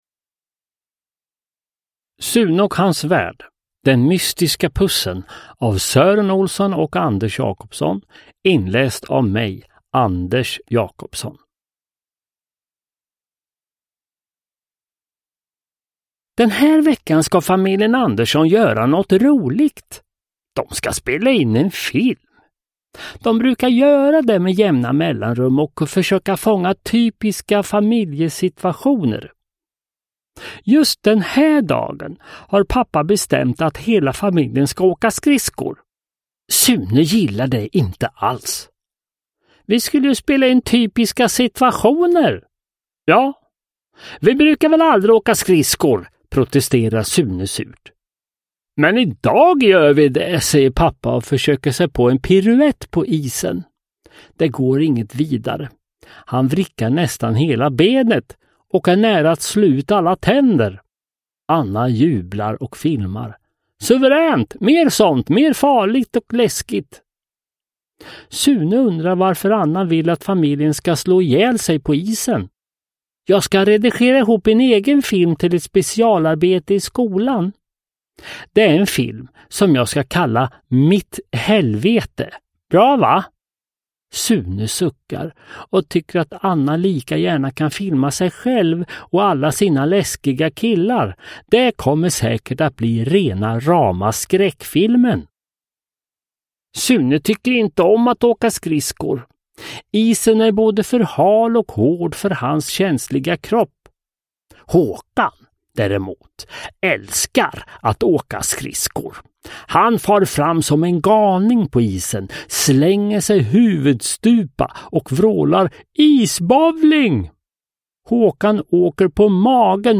Den mystiska pussen – Ljudbok – Laddas ner
Uppläsare: Anders Jacobsson